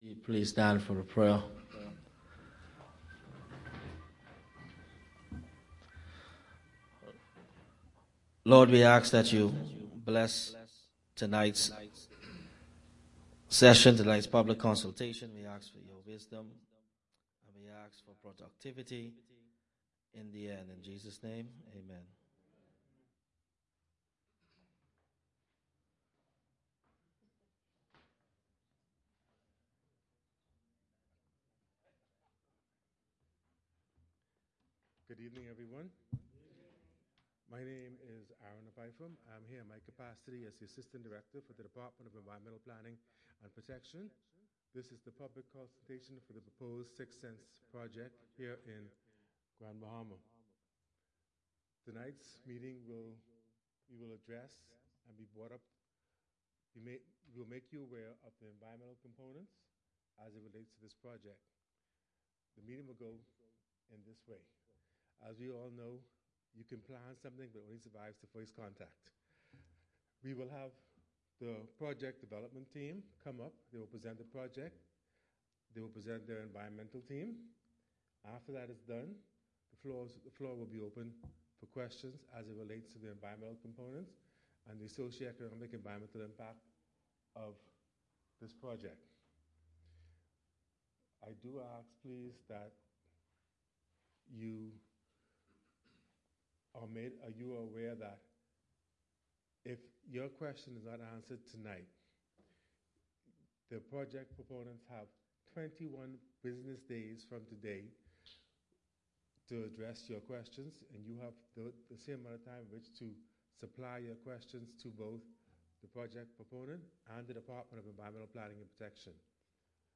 Audio recording of Six Senses Grand Bahama Public Consultation Meeting held on 30 January 2024